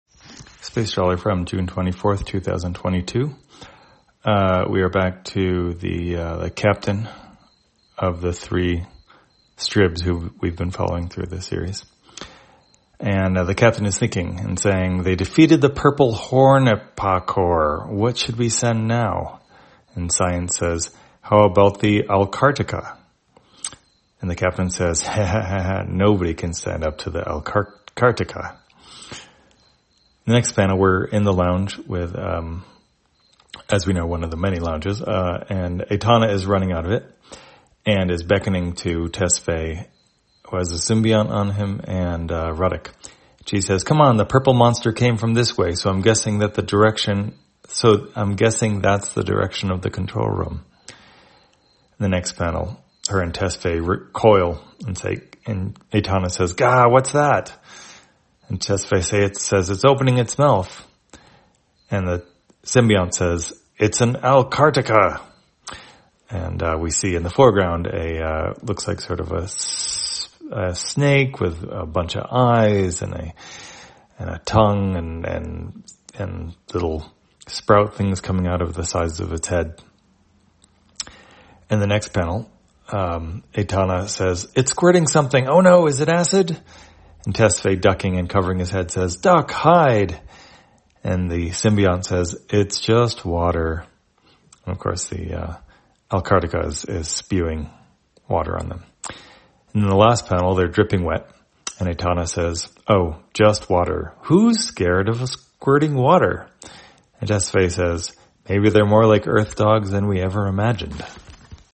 Spacetrawler, audio version For the blind or visually impaired, June 24, 2022.